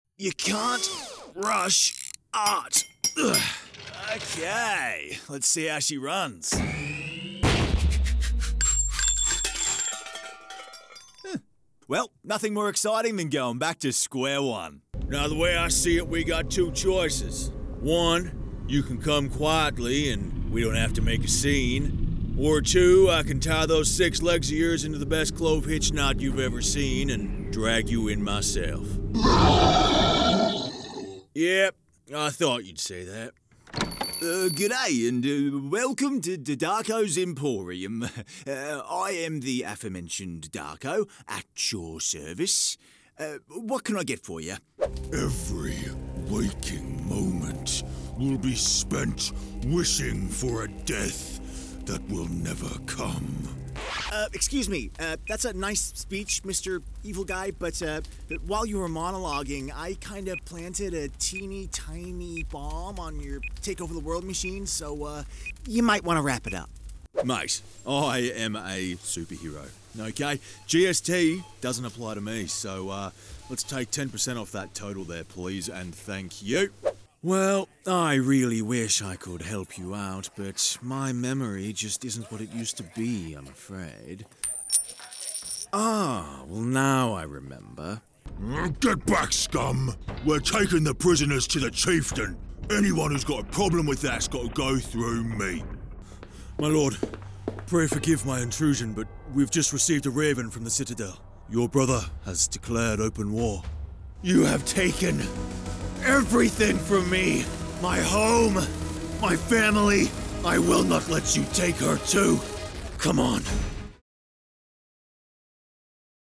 Male
English (Australian)
Yng Adult (18-29), Adult (30-50)
Video Games
0820Character_Reel.mp3